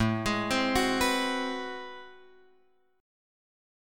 A Minor Major 13th